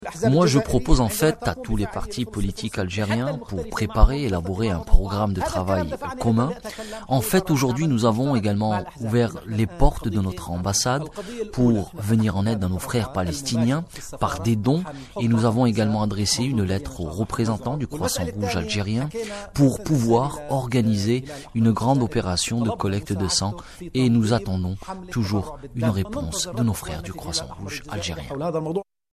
Louai Aissa, ambassadeur de l'Etat de Palestine à Alger